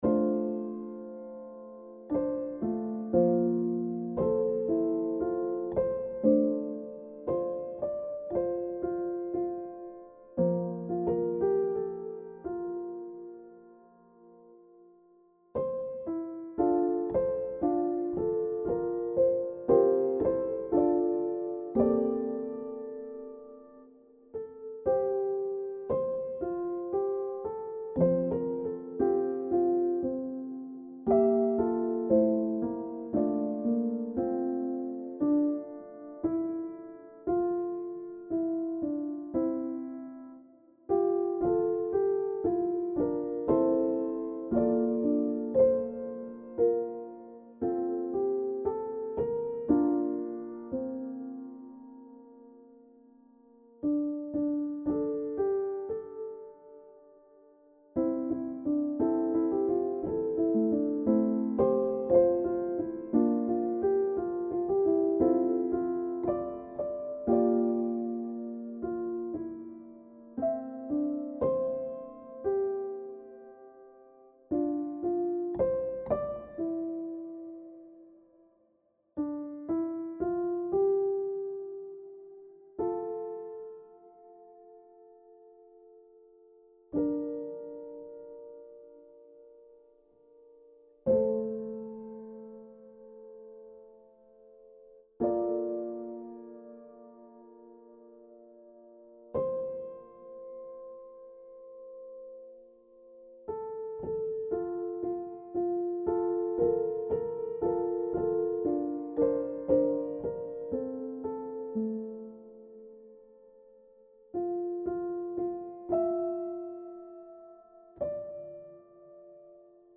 Cycle of Songs: Piano Reduction for 'Infinity'
NOTE: This audio file is a demo version and is for the purpose of helping the choir leader teach their choir the parts of the song.